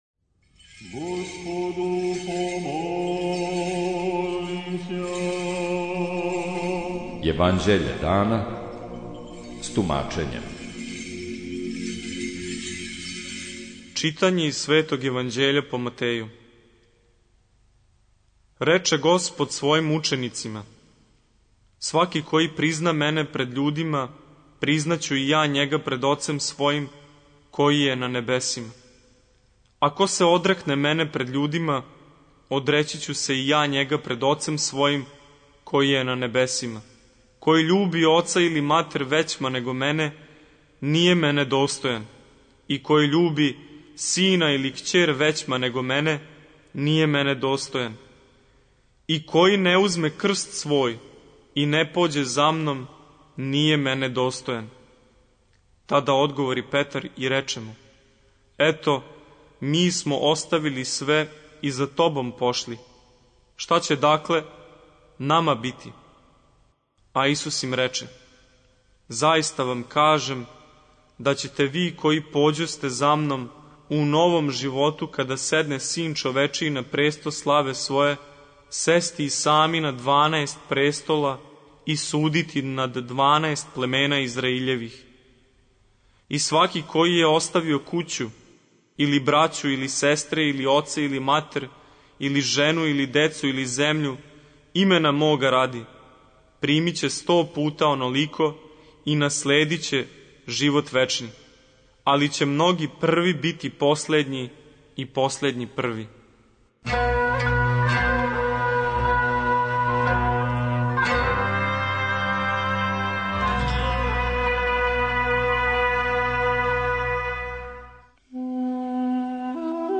Читање Светог Јеванђеља по Матеју за дан 09.04.2026. Зачало 108.